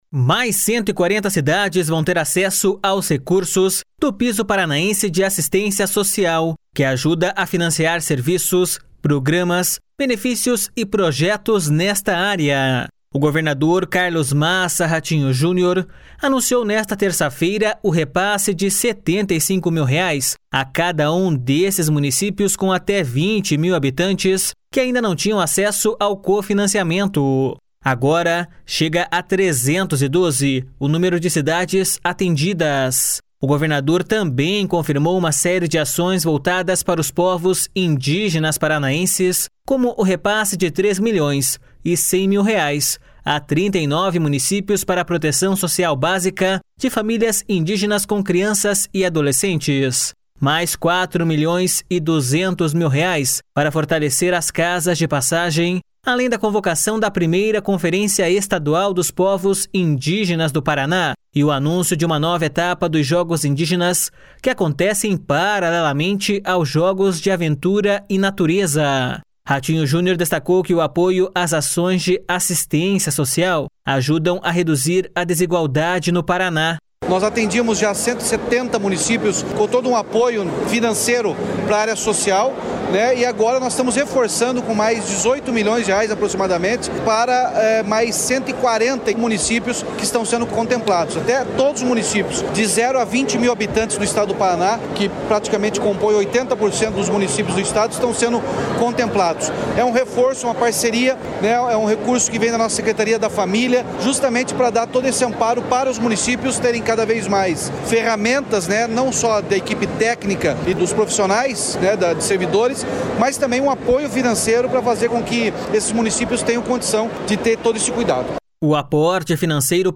Ratinho Junior destacou que o apoio às ações de assistência social ajudam a reduzir a desigualdade no Paraná.// SONORA RATINHO JUNIOR.//
O secretário estadual do Desenvolvimento Social e Família, Rogério Carboni, ressaltou que esse recurso pode financiar ações voltadas às crianças, adolescentes e mulheres em situação de violência.// SONORA ROGÉRIO CARBONI.//
O prefeito de Tupãssi, Luiz Carlos Belletti, afirmou que o recurso extra vai permitir ampliar os projetos que envolvem as pessoas em situação de vulnerabilidade.// SONORA LUIZ CARLOS BELLETTI.//